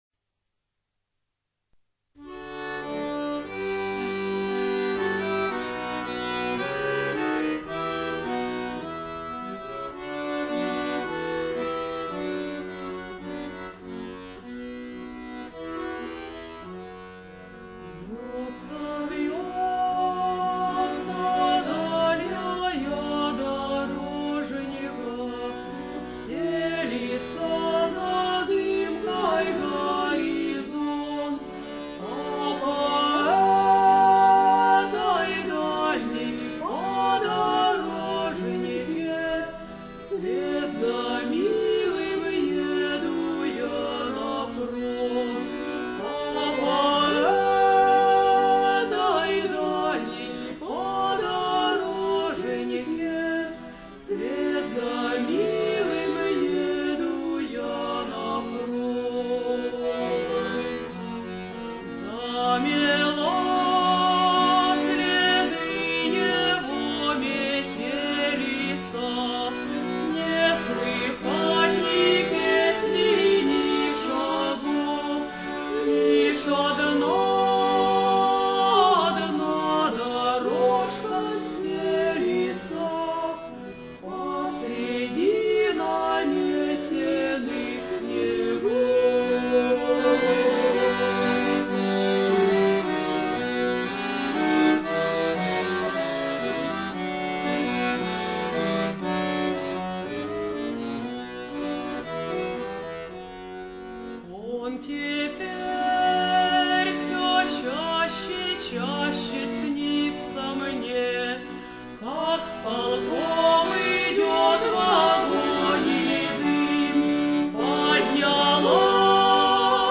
口哨的配器真不错。